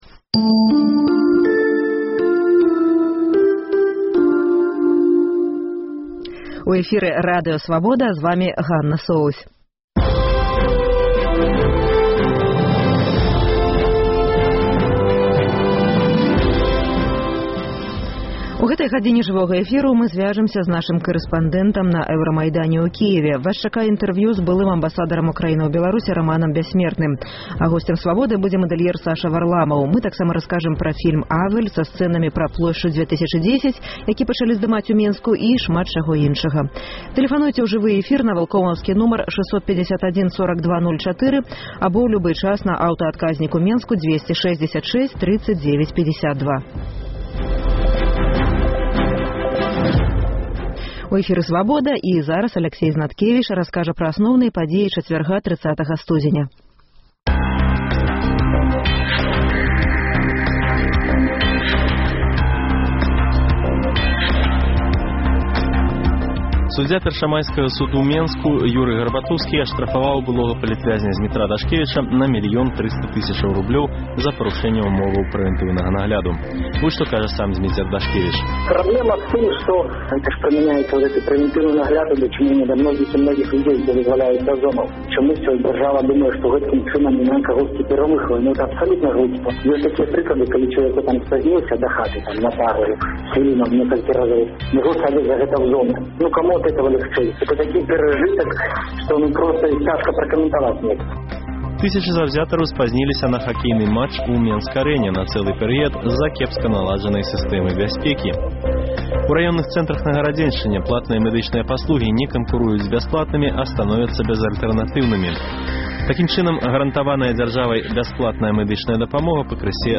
Сёньня ў праграме: 71 дзень Майдану. Жывое ўключэньне з Кіева.